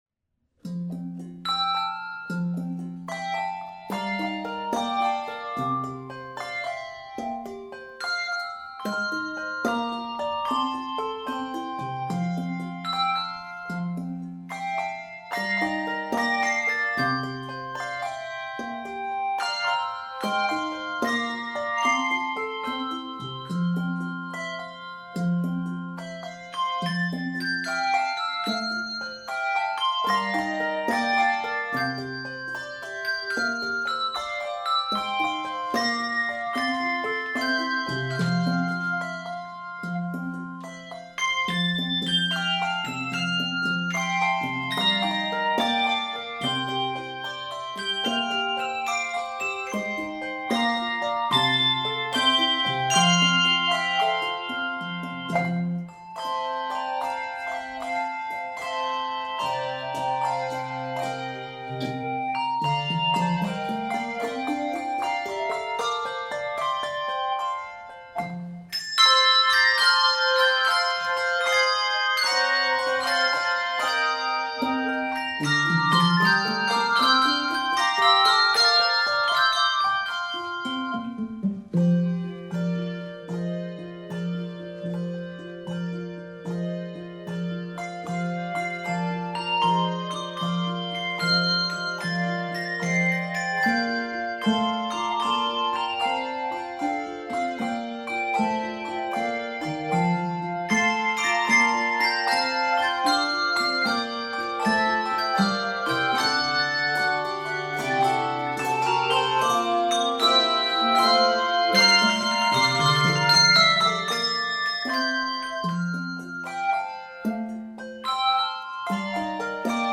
Key of F Major. 111 measures.